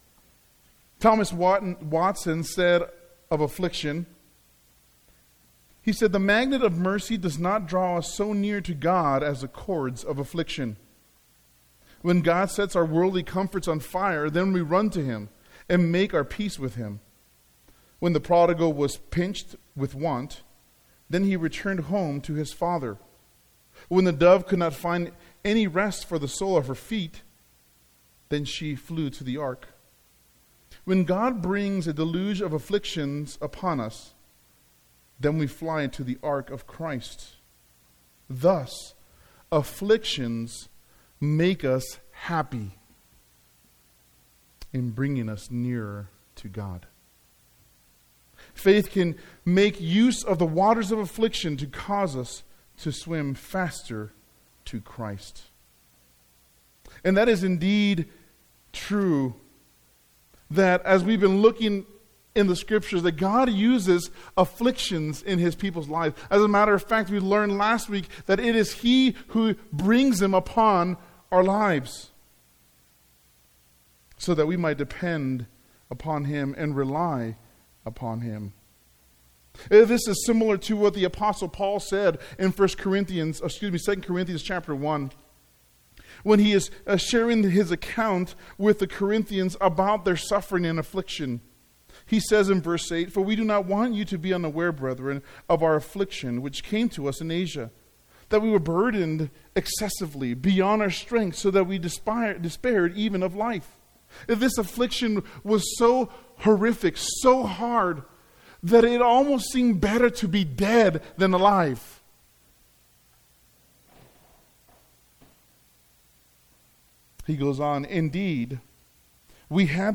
The message from May 15